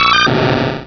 Cri de Kaiminus dans Pokémon Rubis et Saphir.